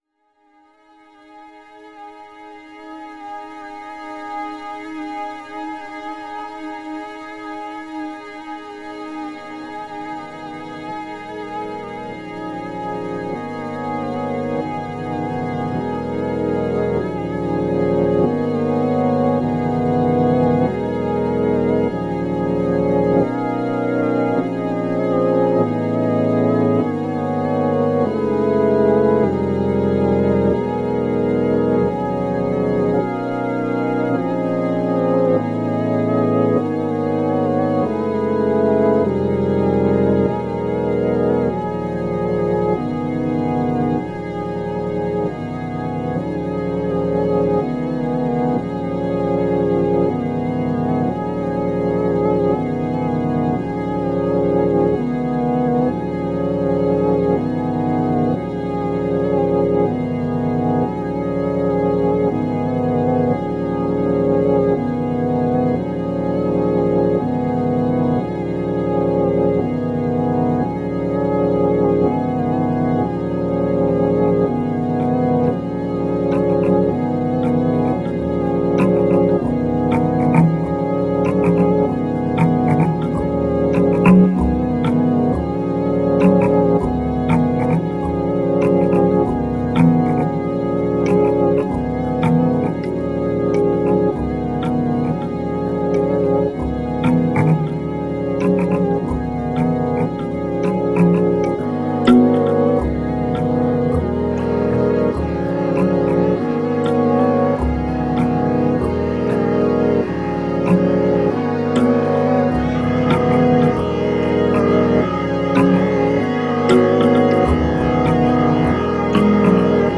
2 analog synthesizers, bass mbira
string sample